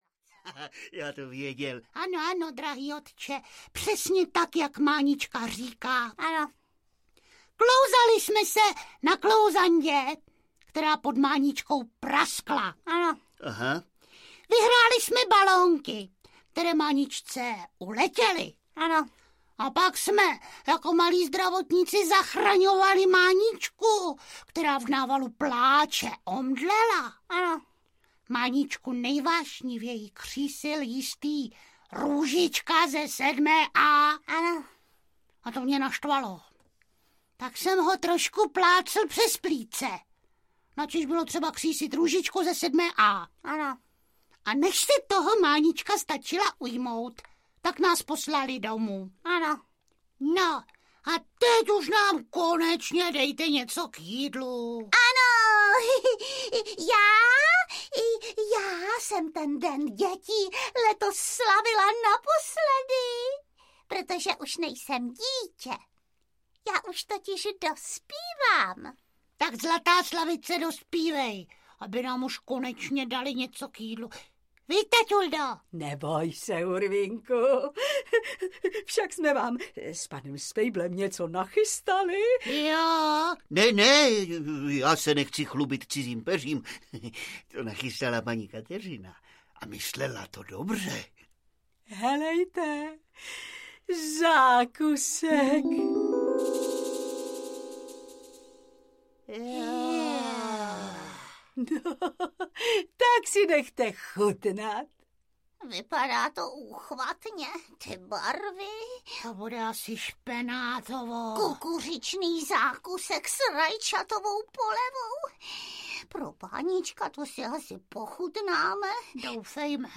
Mlsný Hurvínek audiokniha
Ukázka z knihy
Věřili byste, že při tom všem ani neopustí práh babiččiny kuchyně?Na nahrávce s nečekanou pointou se vedle členů Divadla S+H podíleli také Gabriela Vránová, Chantal Poullain a Jiří Langmajer.